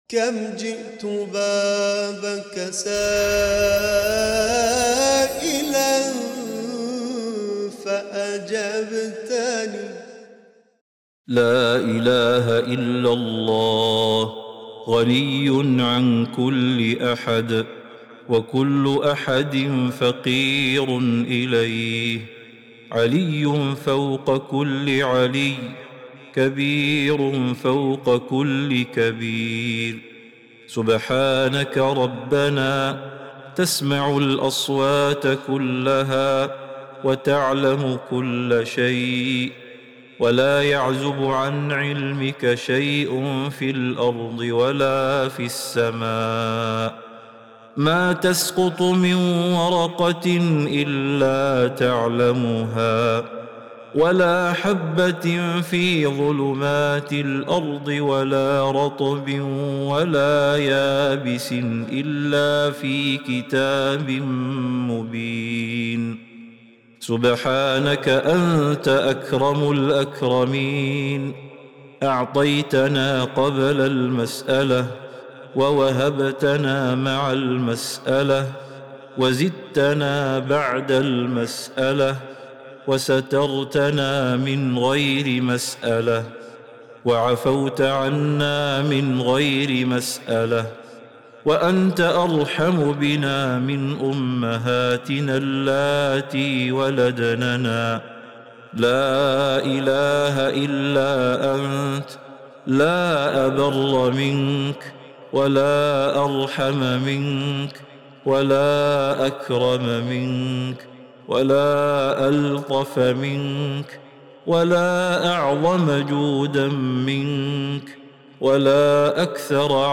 دعاء خاشع ومناجاة مؤثرة تفيض بالثناء على الله تعالى واعترافاً بعظمته وعلمه وجوده. يحتوي على التوسل إلى الله بالستر والعفو والهداية، والطلب بأن يكون الداعي من ورثة جنة النعيم.